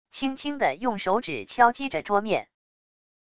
轻轻的用手指敲击着桌面wav音频